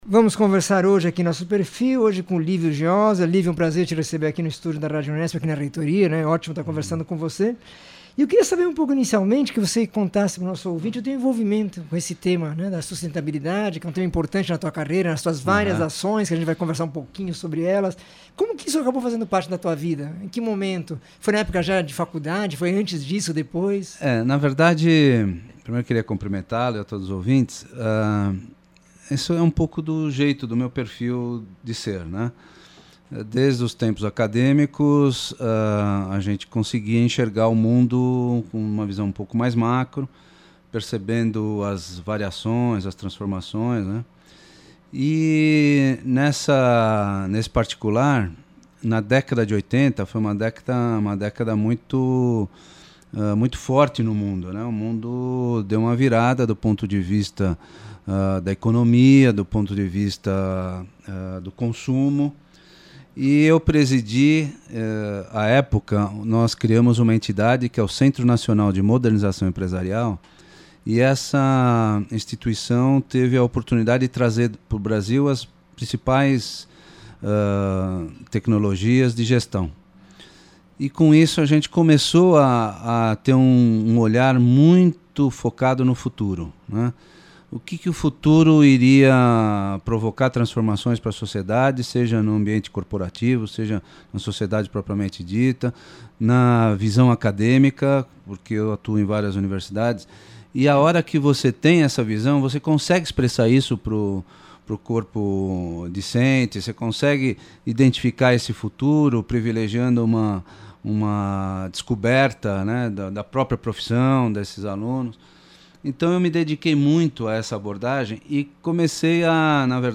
Entrevista com o especialista em administração de empresas.